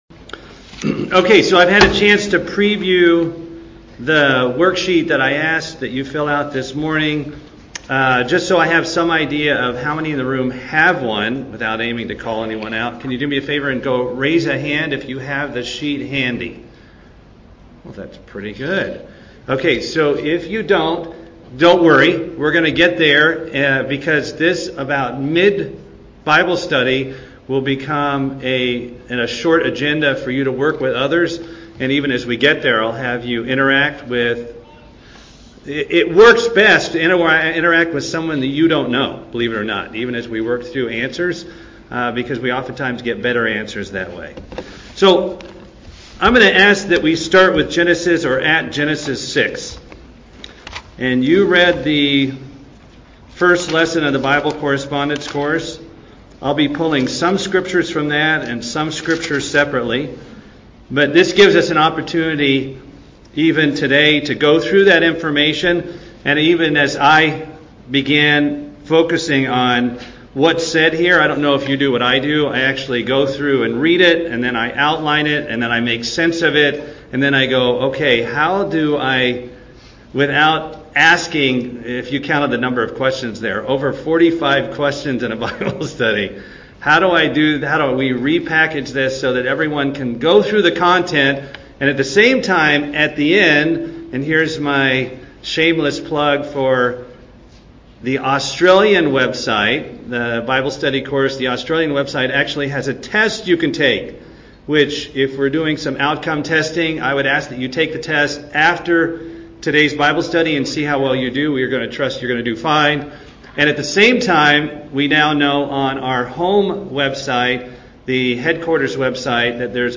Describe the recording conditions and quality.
Given in Atlanta, GA Buford, GA